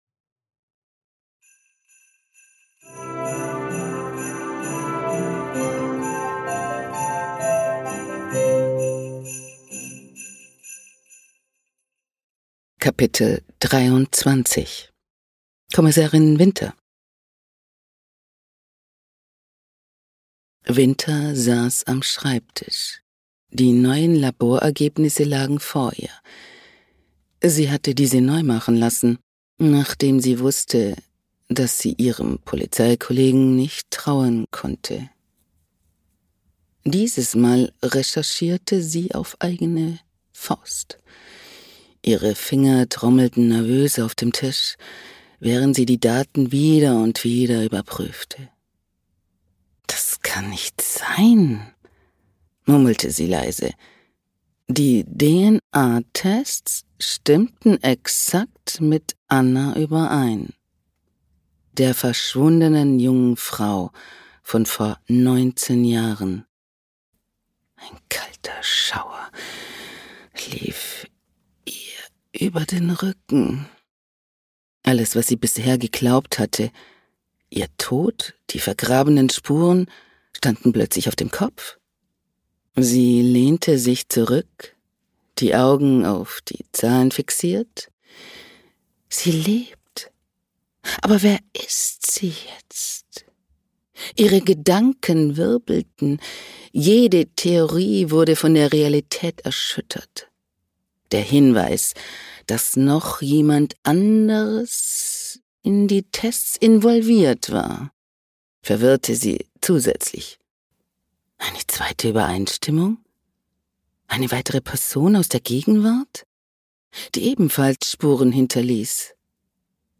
Kriminalgeschichte. Lass dich von acht verzaubernden Stimmen in die